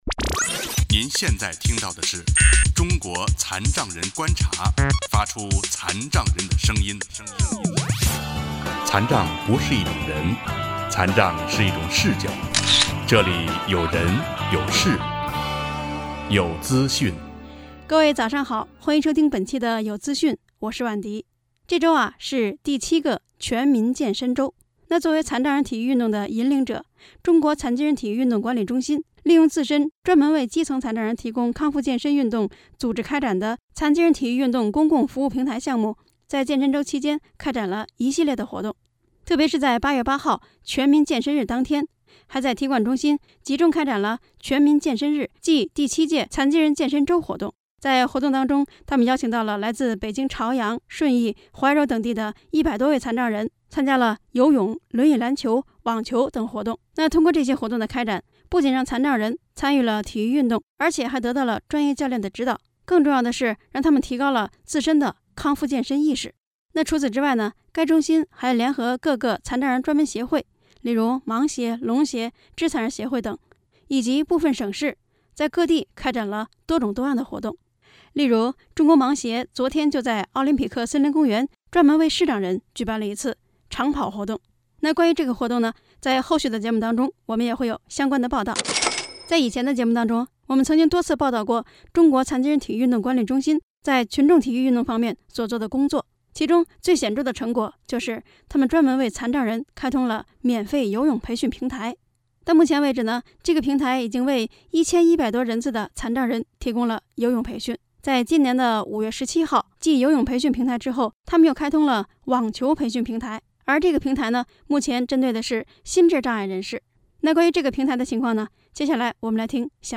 中央人民广播电台残疾人之友节目对中心平台建设的系列报道（四）